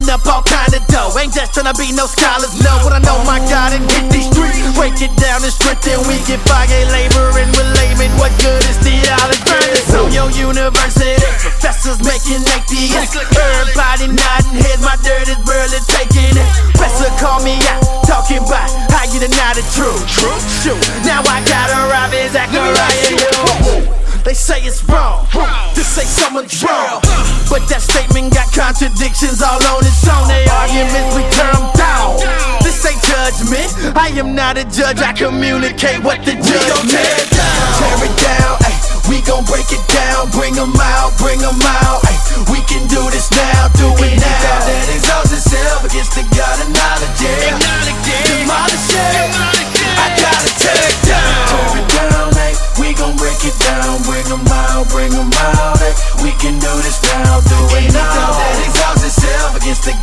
deren Sound von Urban-Beats geprägt ist.
• Sachgebiet: Rap & HipHop